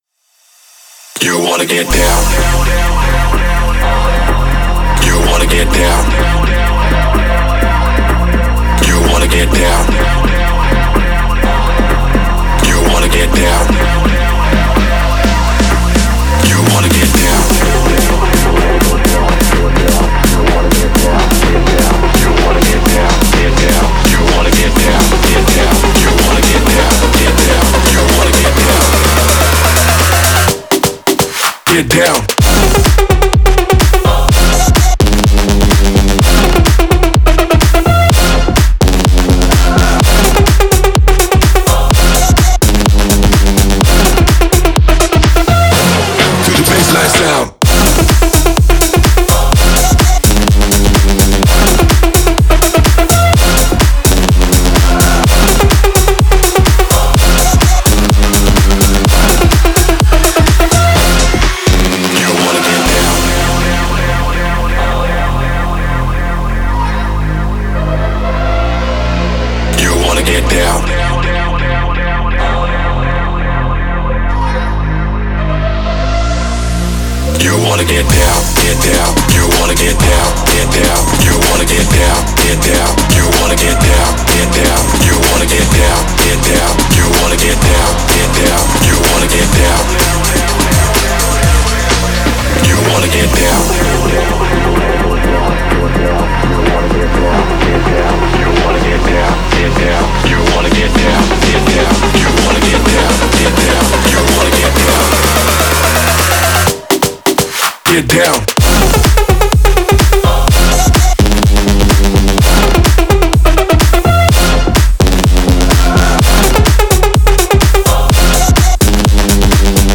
это энергичная песня в жанре хип-хоп
Яркие вокалы и динамичные инструменталы